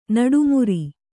♪ naḍu muri